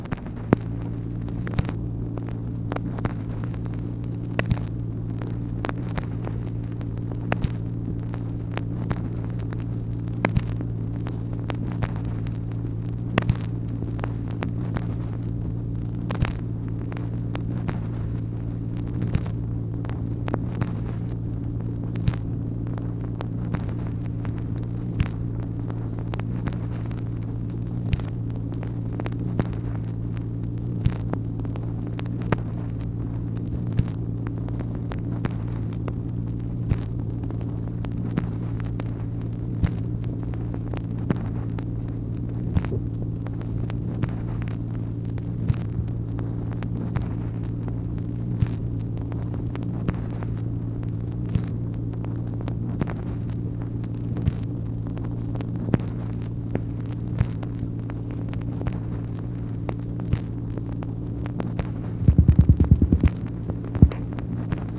MACHINE NOISE, January 8, 1964
Secret White House Tapes | Lyndon B. Johnson Presidency